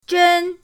zhen1.mp3